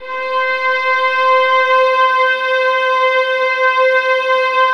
VIOLINS DN5.wav